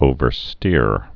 (ōvər-stîr)